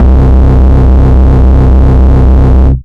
Standard 808 (JW3).wav